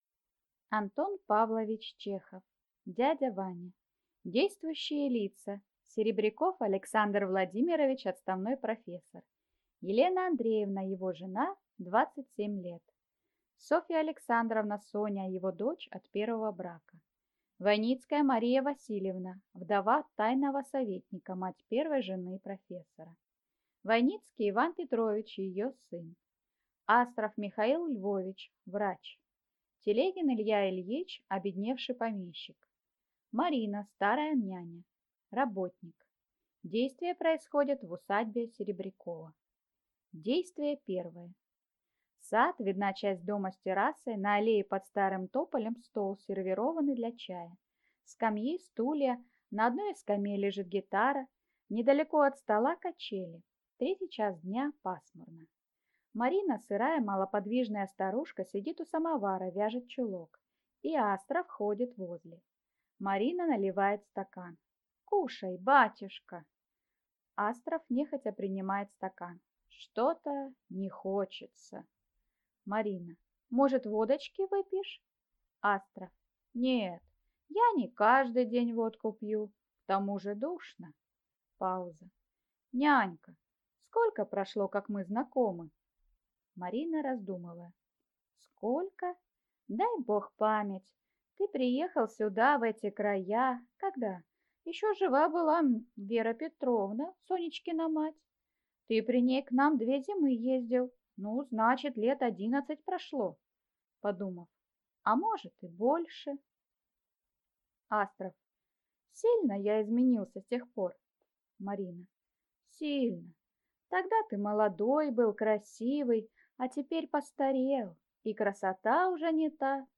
Аудиокнига Дядя Ваня - купить, скачать и слушать онлайн | КнигоПоиск